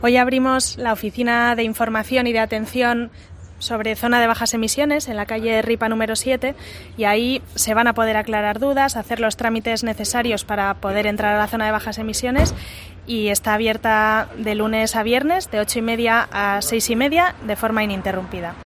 Nora Abete, concejala de movilidad de Bilbao, informa sobra la oficina de información de la ZBE